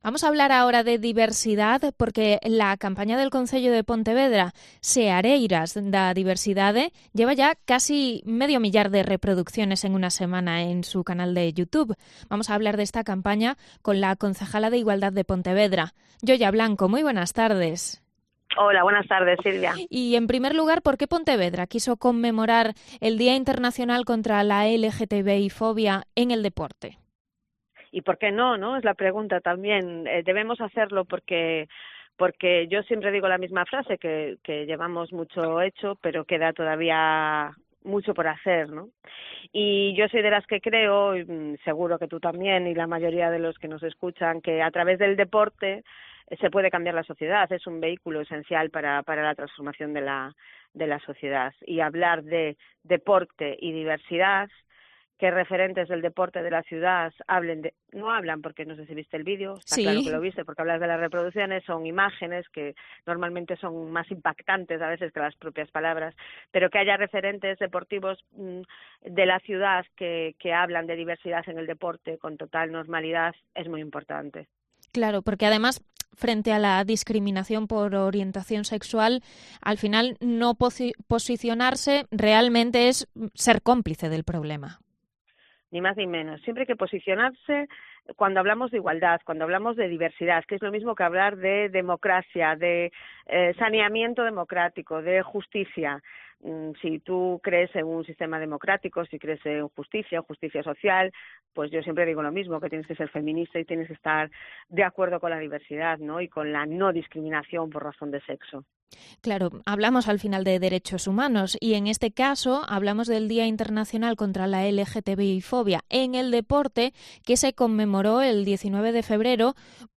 En COPE, la concejala de Igualdad, Yoya Blanco, ha agradecido la participación de todos aquellos que han querido psoicionarse en favor de los derechos humanos.